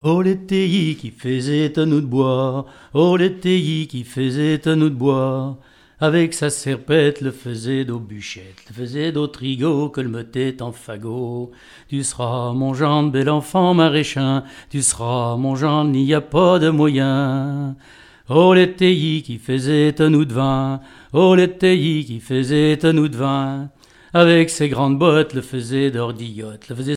Langue Patois local
Genre laisse
répertoire de chansons, et d'airs à danser
Catégorie Pièce musicale inédite